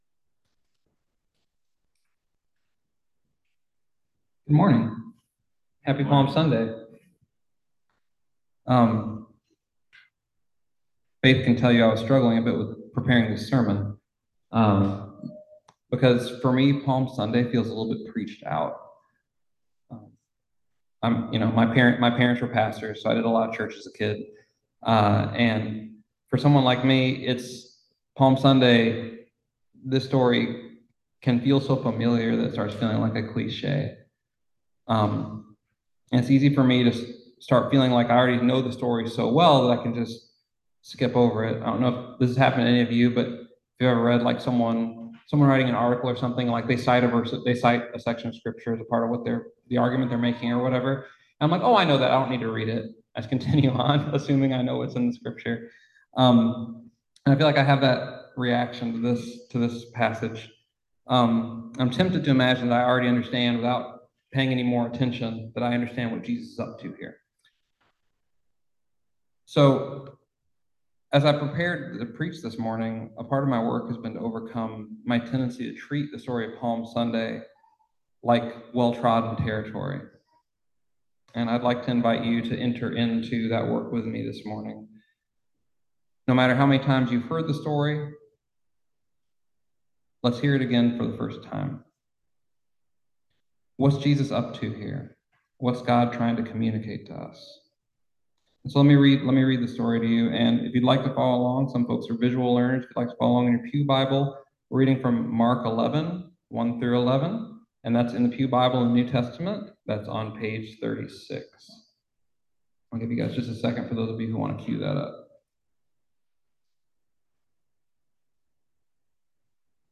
Listen to the most recent message from Sunday worship at Berkeley Friends Church, “Hosanna!”